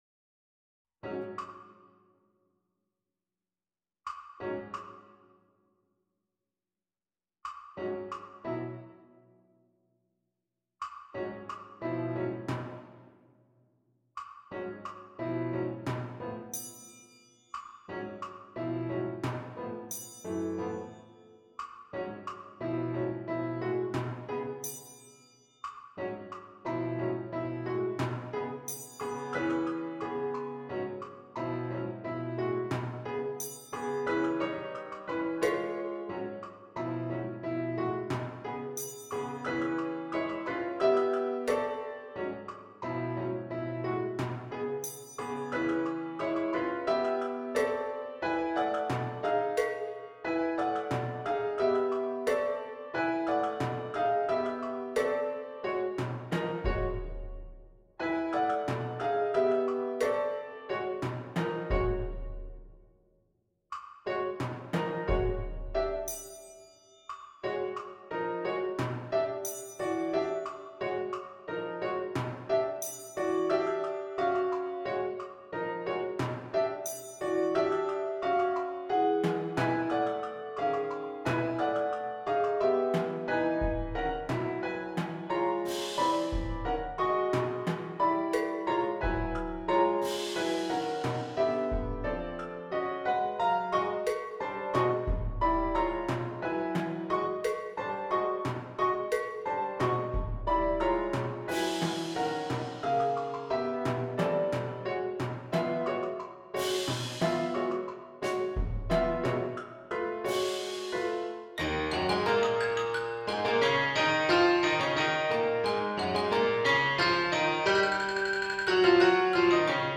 MIDI Recording
In general, the piece evolves from hocketing between the percussion and piano to playing in rhythmic unison, and back.